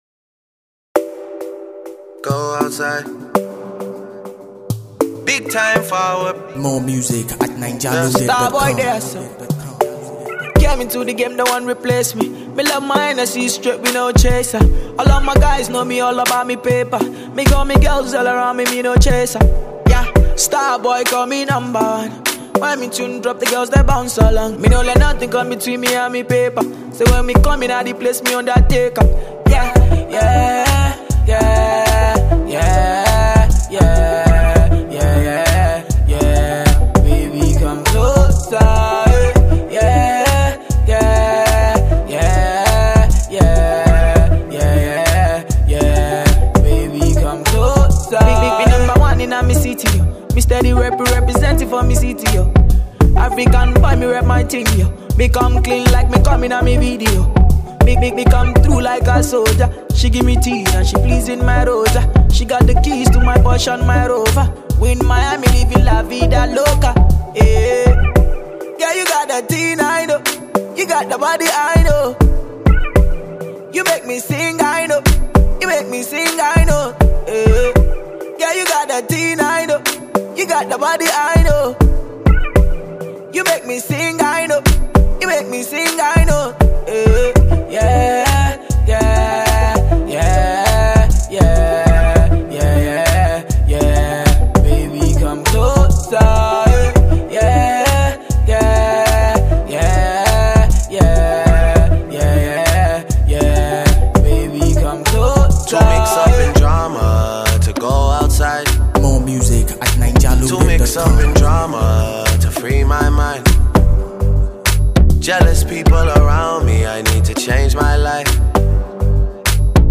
dancehall inspired track
Enjoy this catchy and tropical tune.